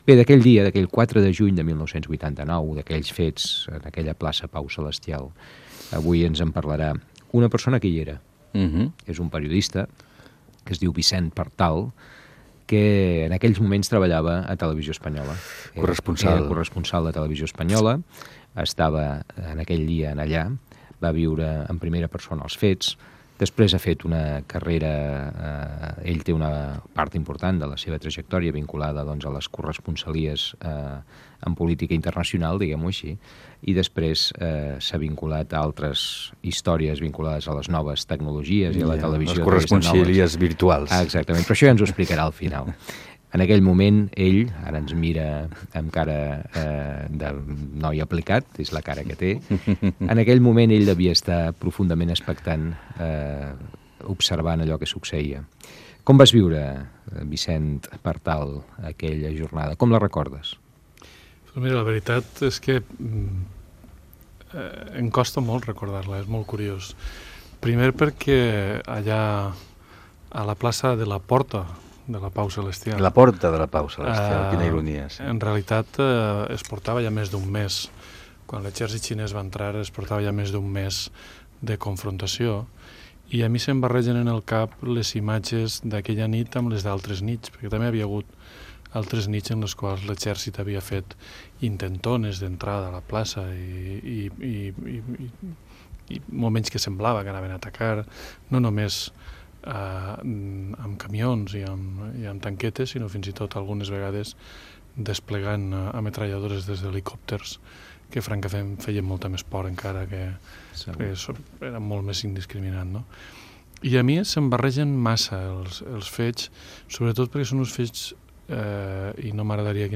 Fragment d'una entrevista al periodista Vicent Partal. Recorden el 4 de juny de 1989 quan es va produir la masacre de la Plaça de Tiananmén a la Xina.